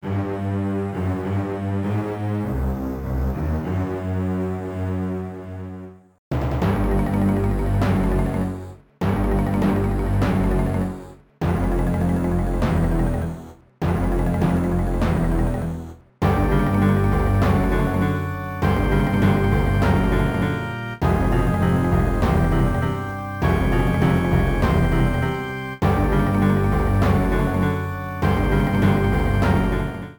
Ripped from game data, then trimmed in Audacity